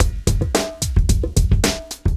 110LOOP B7-R.wav